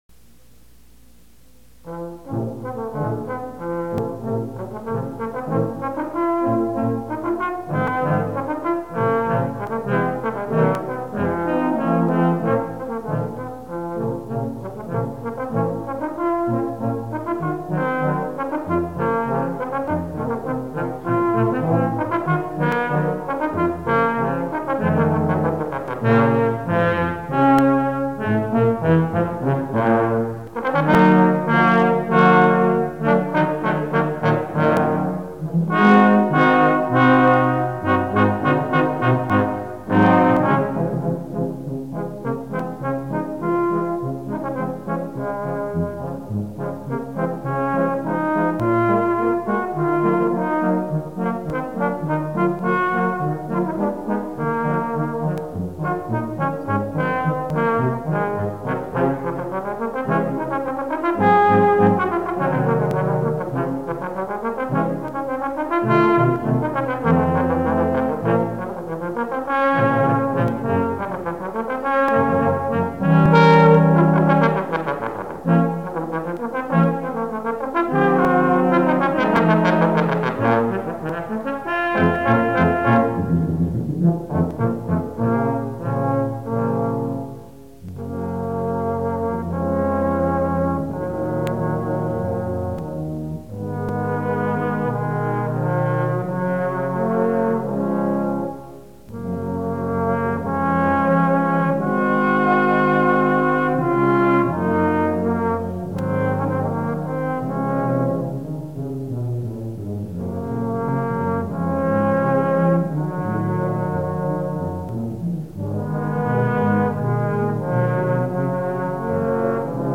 trombone
. we were real amateurs, of course, but we had fun.   I made a few mistakes on this piece, but it wasn't too bad so I thought I'd just put it up and take my hits . . . hahahaha   After clicking, The files should play automatically on your Windows Media Player.